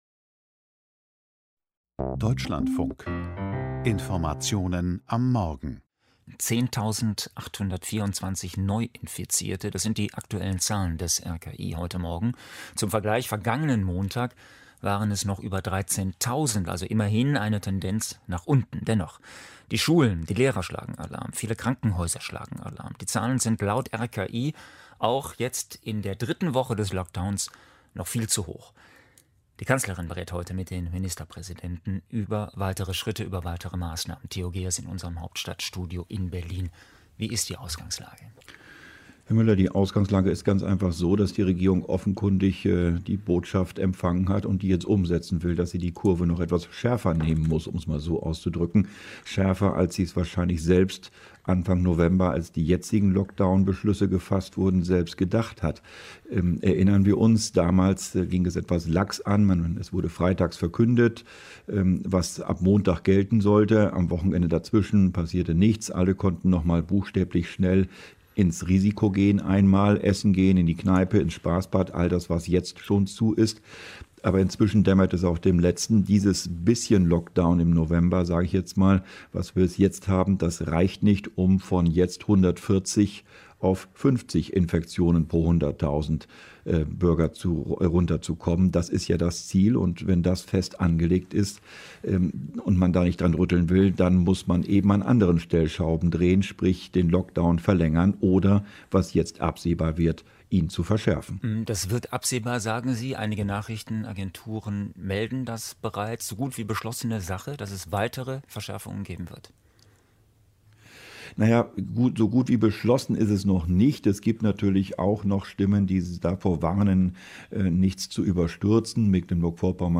Vorbericht des Dlf vom 24.11.2020 zur Lockdown II – Verlängerung: